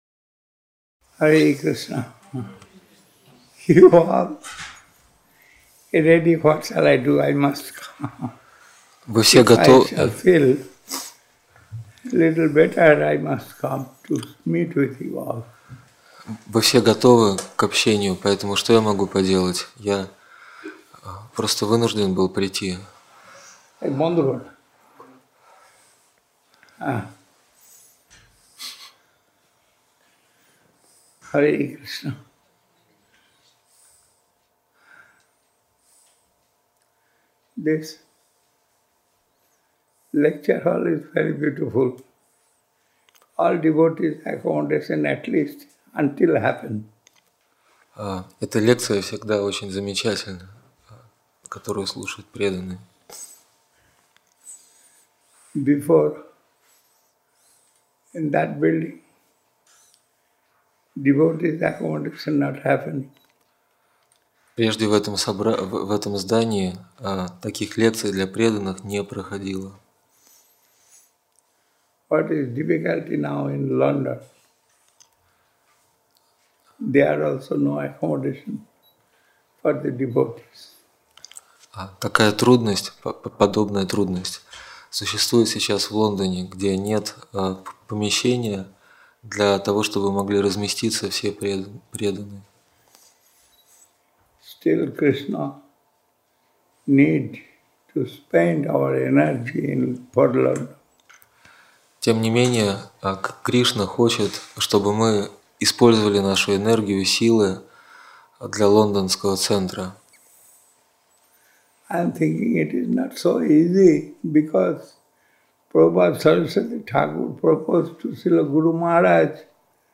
Даршаны 2006